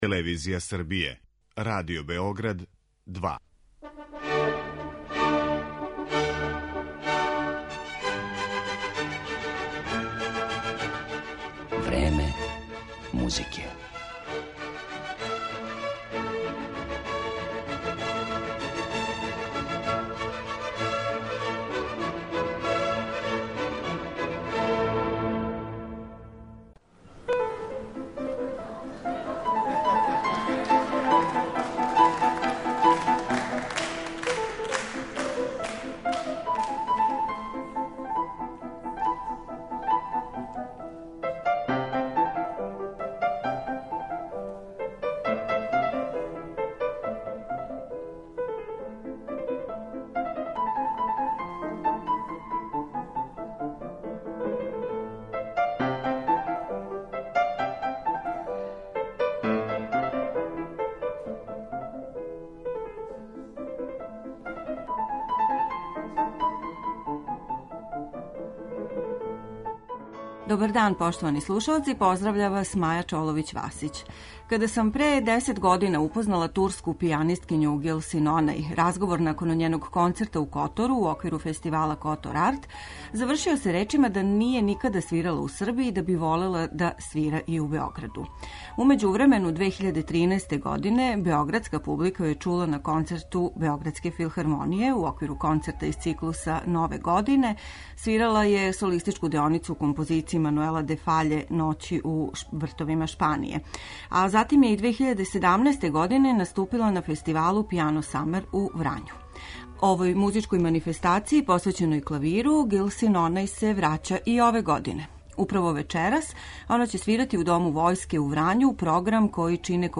Турска пијанисткиња – Гулсин Онај
Данас ћемо је представити музиком Моцарта, Шопена, Рахмањинова, Сајгуна и Чајковског, као и кроз делове интервјуа који је забележен у Котору 2009. године.